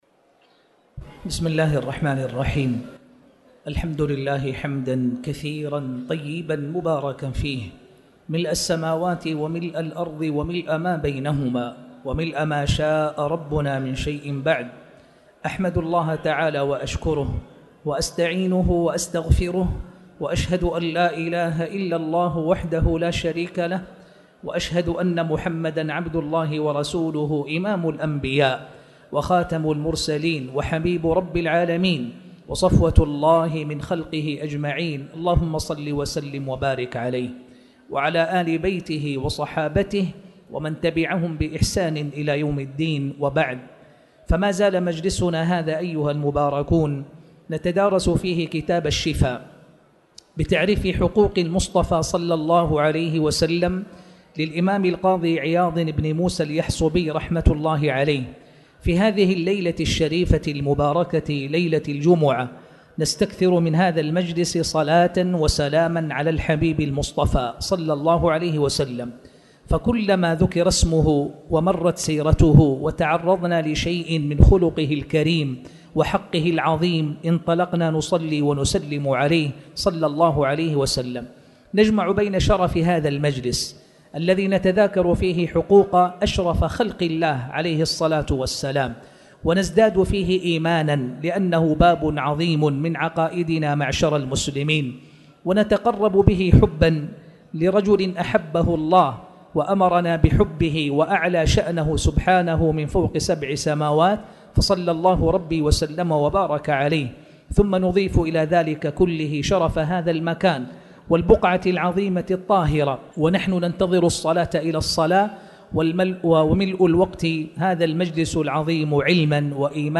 تاريخ النشر ١٧ ربيع الثاني ١٤٣٩ هـ المكان: المسجد الحرام الشيخ